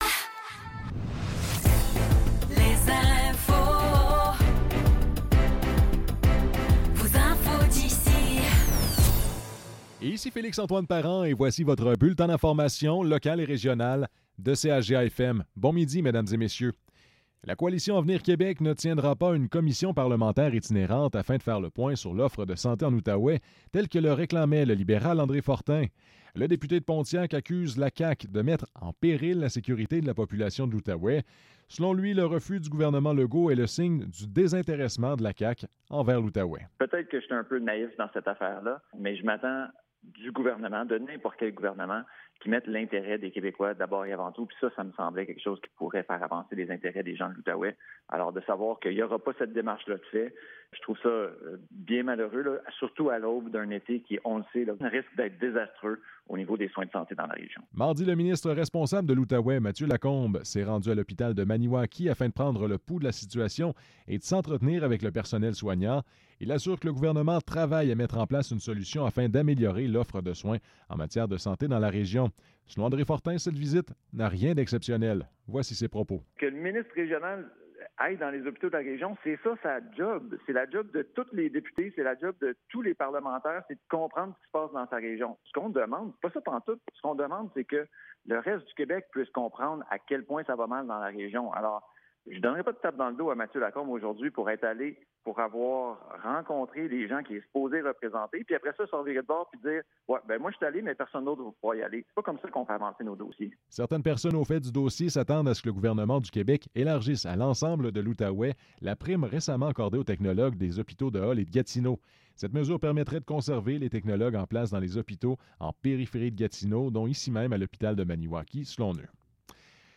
Nouvelles locales - 7 juin 2024 - 12 h